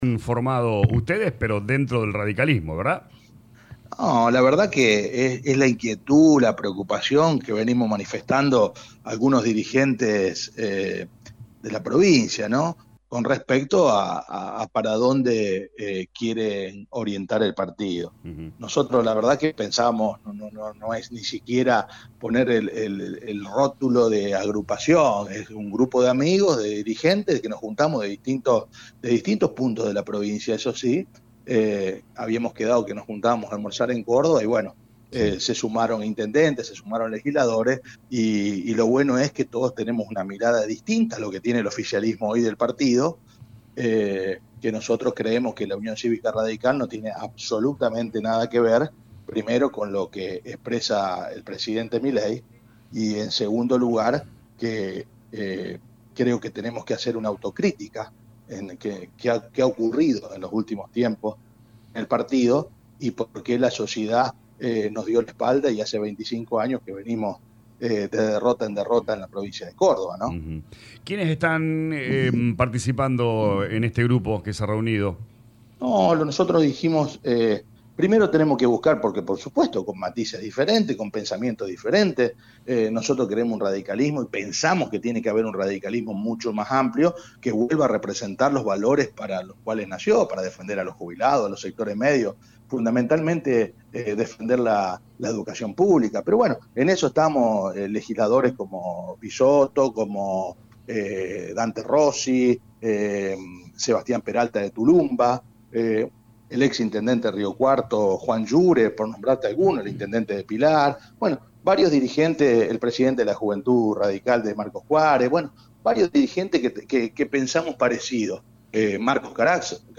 Cristian Canalis, Tribuno de Cuentas en San Francvisco, uno de los referentes de San Justo en esta nueva línea, dió su opinion en LA RADIO 102.9.